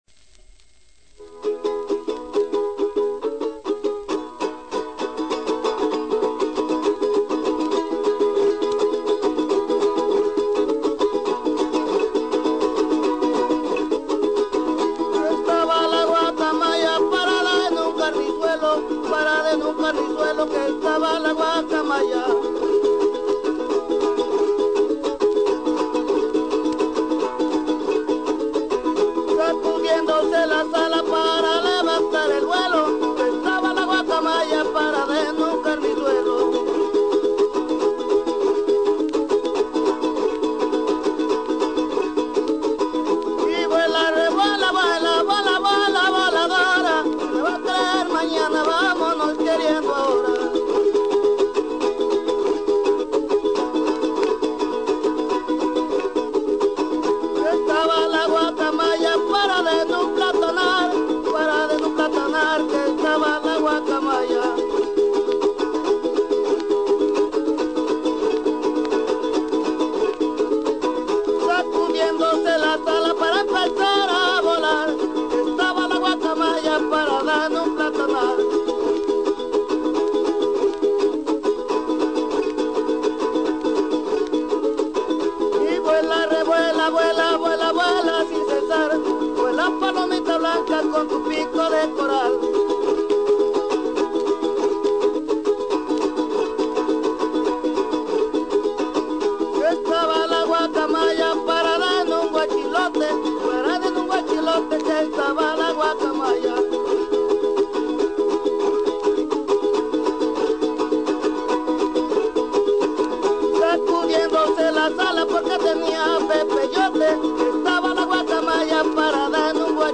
Son jarocho.
Grabado en Otatitlán
con jarana segunda.